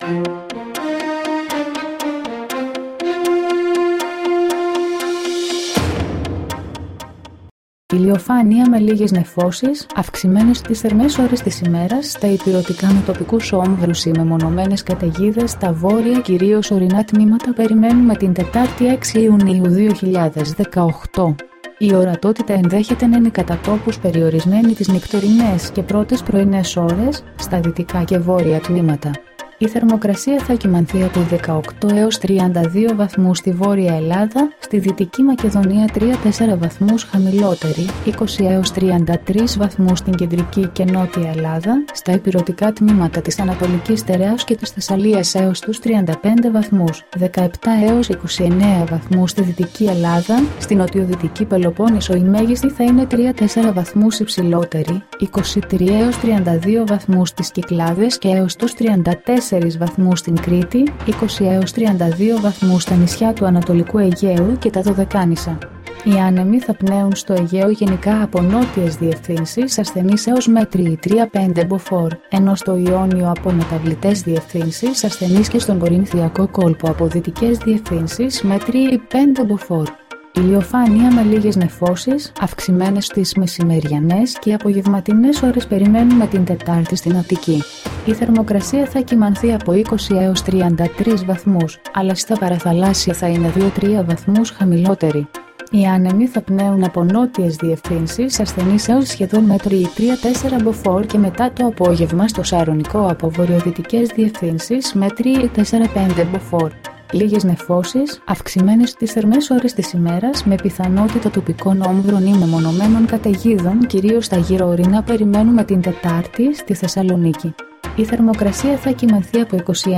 dailyforecast22.mp3